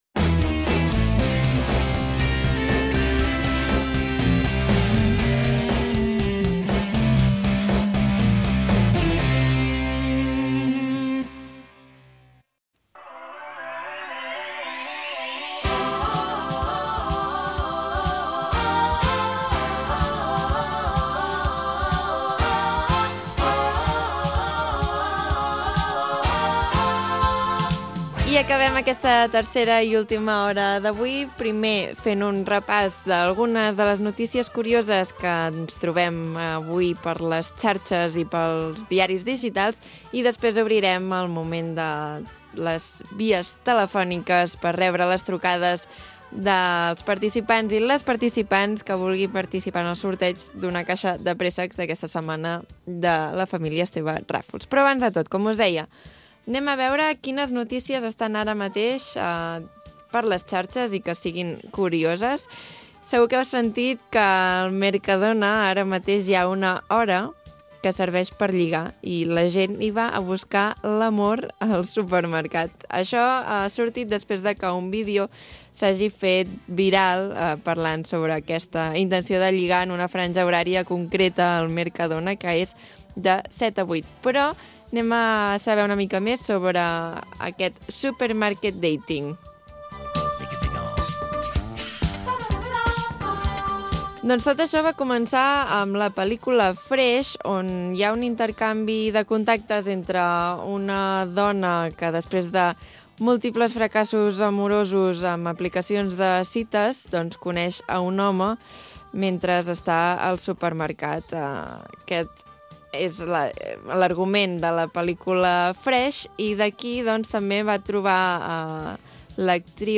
Magazín diari d'estiu